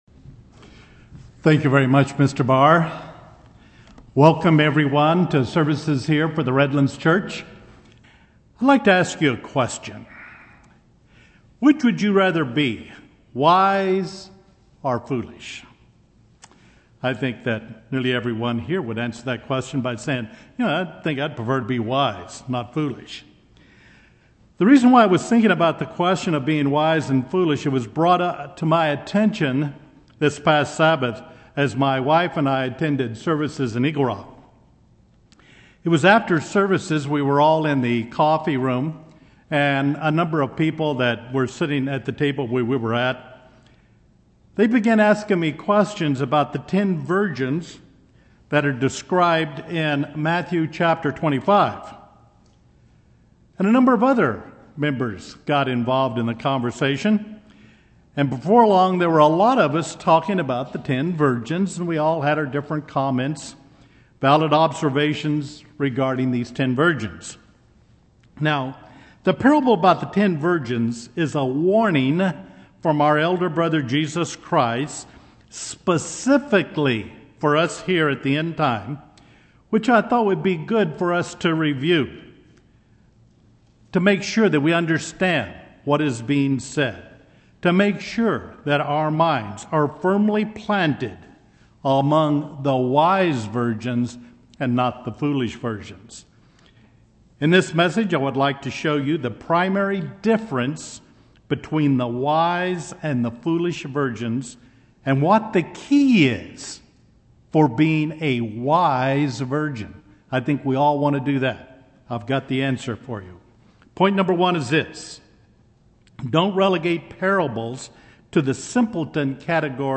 Given in Redlands, CA
UCG Sermon Studying the bible?